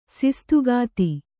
♪ sistugāti